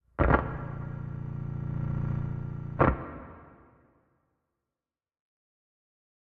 Minecraft Version Minecraft Version snapshot Latest Release | Latest Snapshot snapshot / assets / minecraft / sounds / ambient / nether / warped_forest / mood4.ogg Compare With Compare With Latest Release | Latest Snapshot